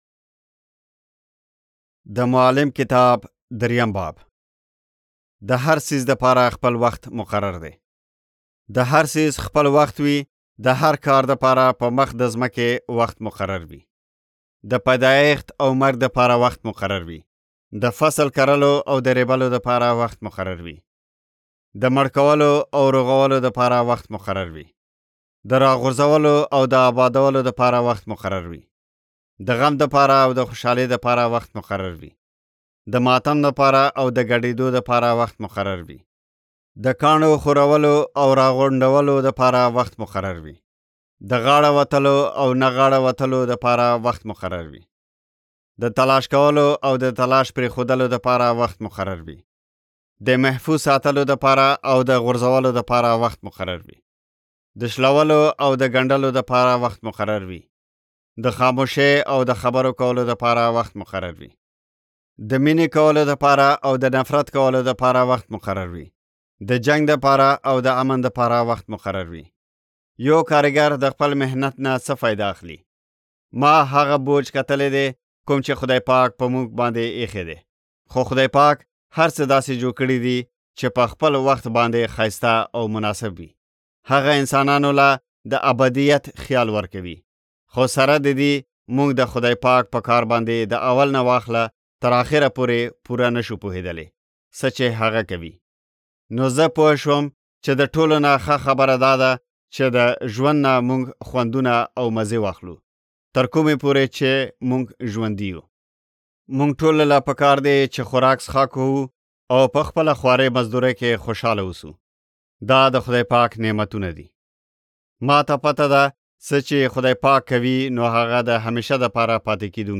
Ecclesiastes Audiobook (YZ) – Pashto Zeray
يوسفزئ ختیځ افغانستان ختیځ افغانستان